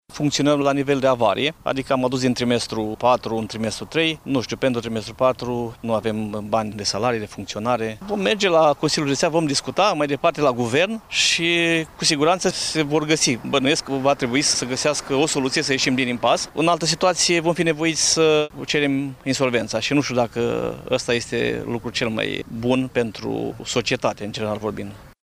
Primarul comunei Puiești, Ticu Cezar, declară că, în lipsa unei intervenții urgente din partea Guvernului, activitatea primăriei riscă să fie suspendată: